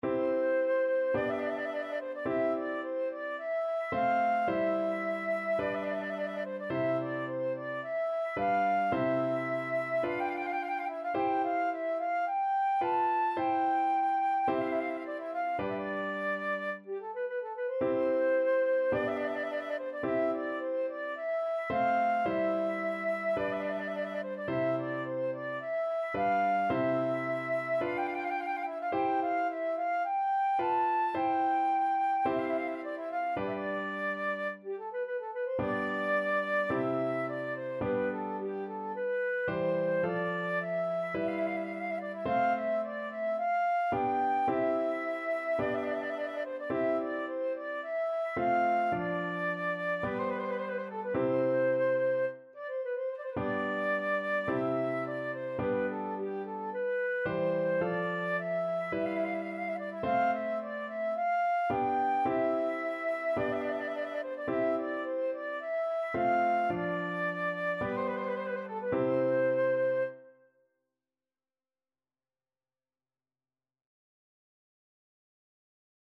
Flute
4/4 (View more 4/4 Music)
C major (Sounding Pitch) (View more C major Music for Flute )
= 54 Slow
Classical (View more Classical Flute Music)